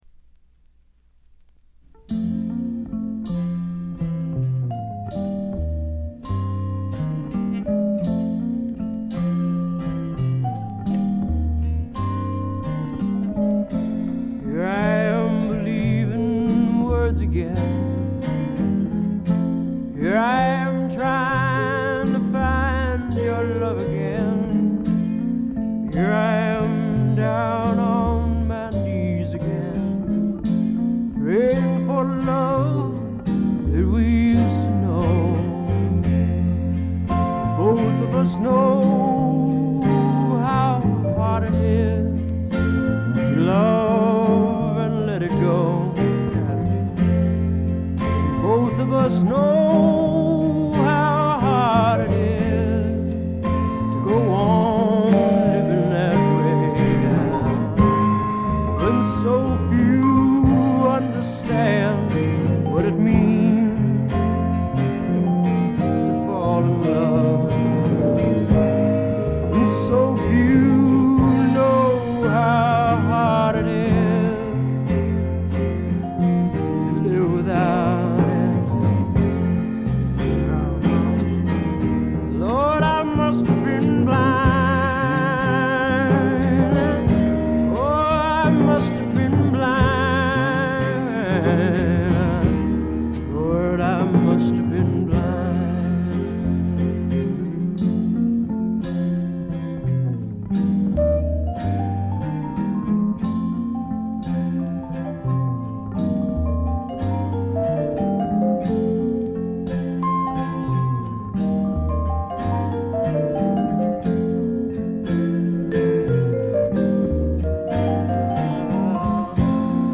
軽微なバックグラウンドノイズ、チリプチ少し。
内省的で沈うつなムードに包まれたひりひりする感覚は一度味わったら思い切り癖になります。
アシッド・フォーク好きならもちろん基本ですが、一般のシンガー・ソングライター・ファンにも強力にオススメできるものです。
試聴曲は現品からの取り込み音源です。